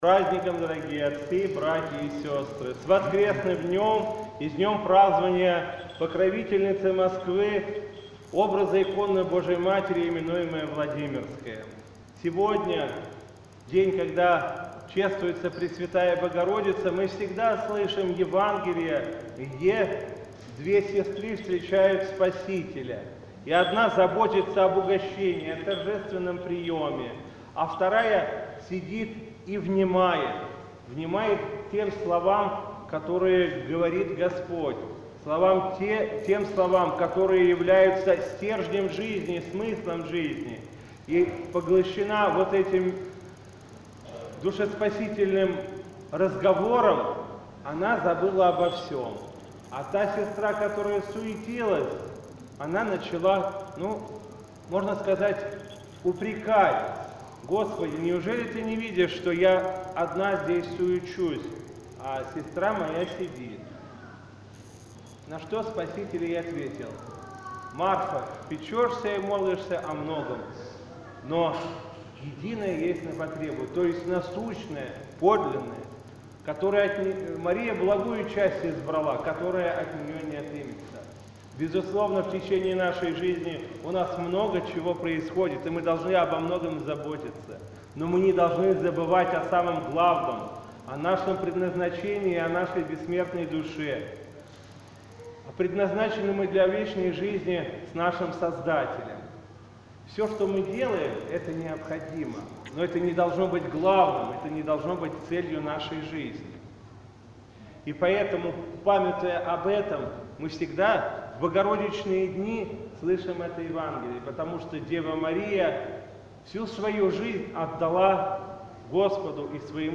По окончании богослужения в центре храма было совершено славление Пресвятой Богородице и митрополит Игнатий обратился к присутствующим с архипастырским словом на тему воскресного Евангельского чтения о Марфе и Марии.